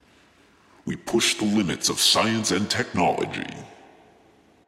描述：科幻相关的文字由男性说。用AT2020 + USB录制。有效果。